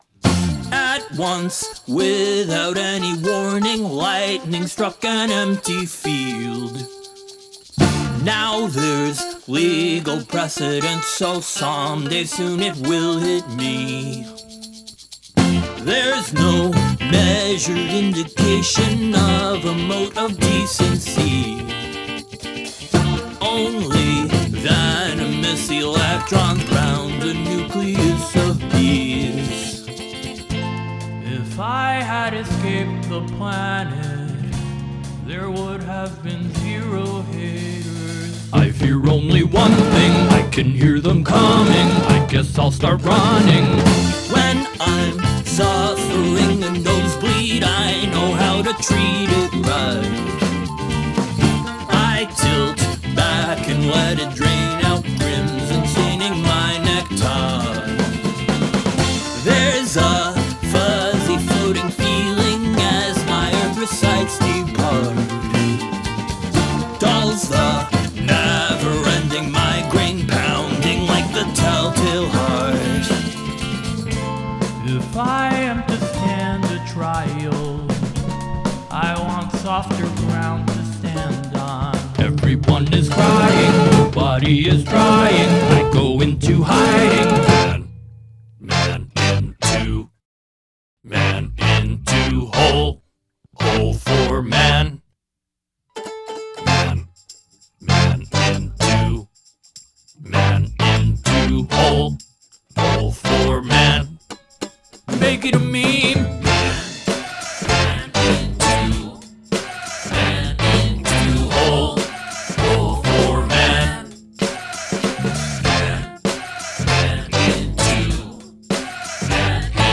post-mcluhanist chamber punk for the cultural epidemic